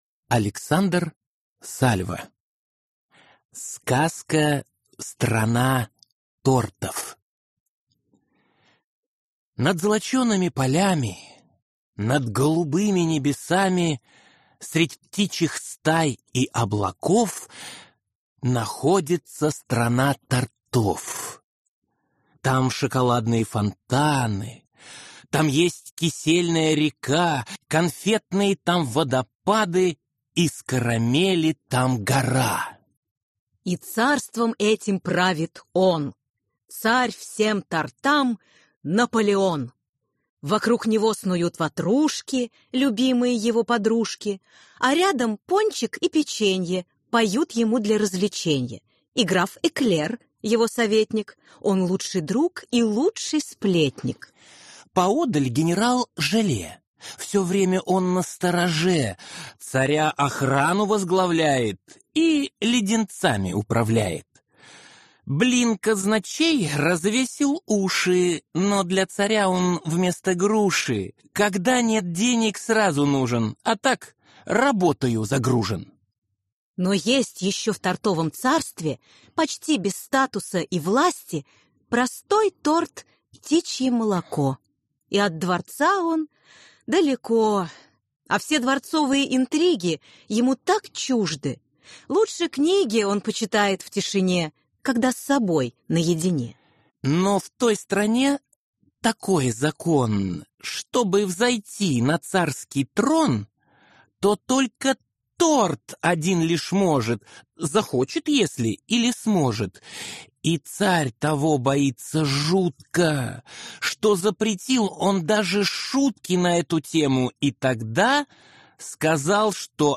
Аудиокнига Страна тортов | Библиотека аудиокниг